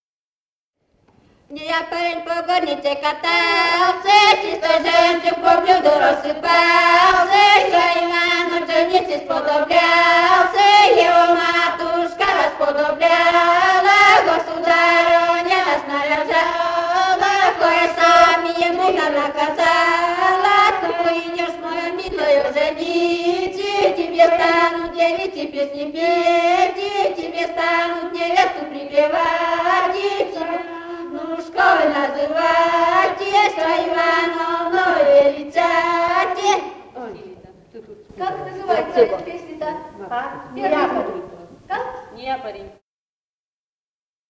Не якорень по горнице катался (свадебная).